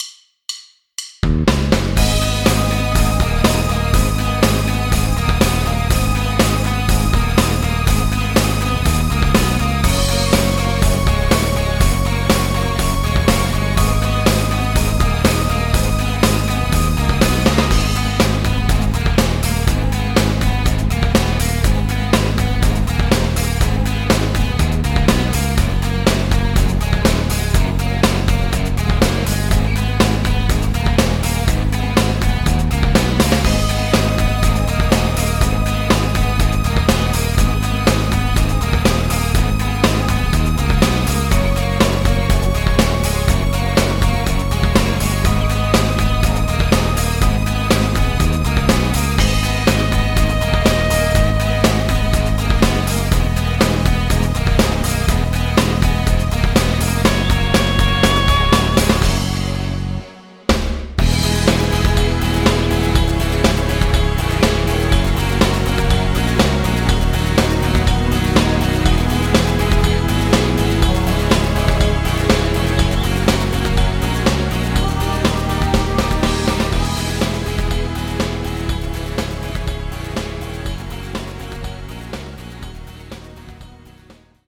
karaoke, instrumental